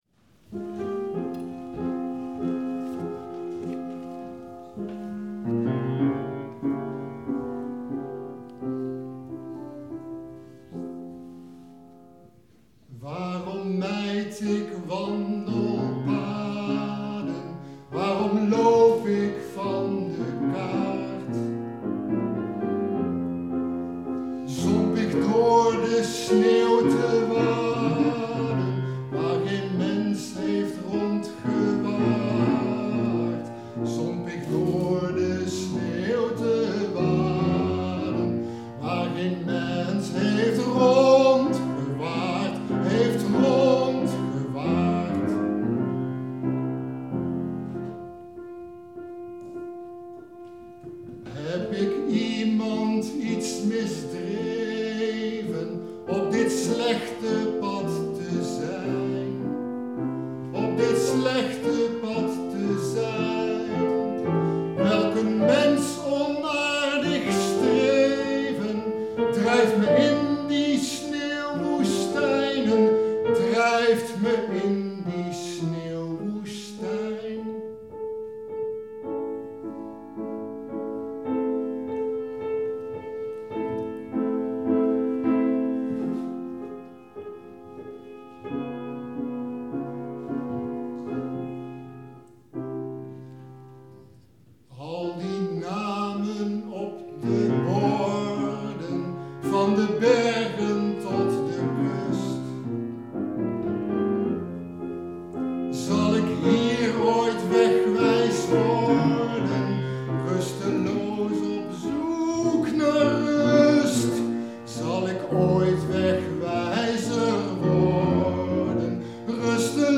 Hier kun je mijn zang beluisteren:
twee stukken met pianobegeleiding